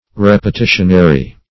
Repetitionary \Rep`e*ti"tion*a*ry\ (-?-r?), a. Of the nature of, or containing, repetition.